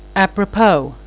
'aprəpəv/ adv., a., n., & prep. Also à propos/